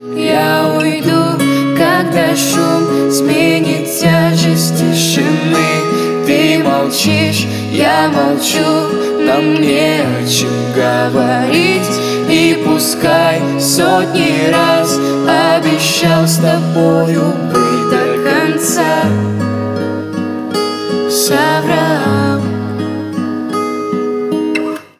гитара
грустные
спокойные
дуэт
женский и мужской вокал